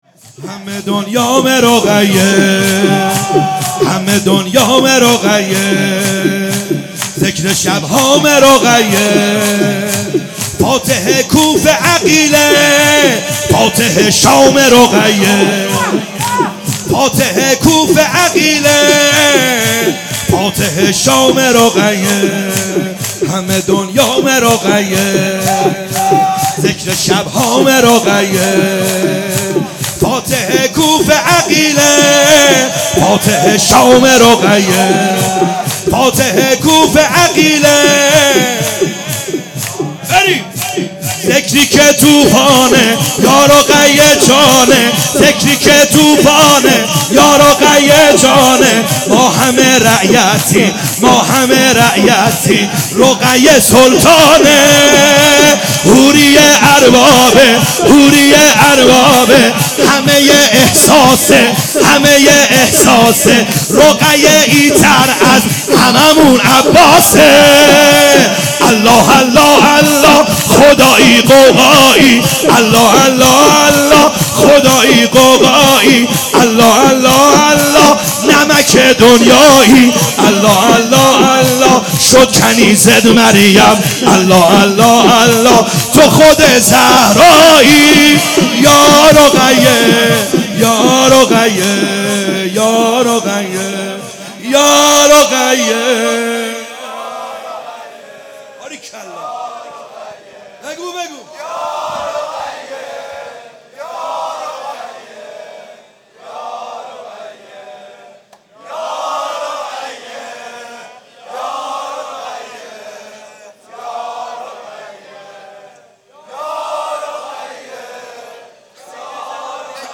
خیمه گاه - بیرق معظم محبین حضرت صاحب الزمان(عج) - شور ا همه دنیامه رقیه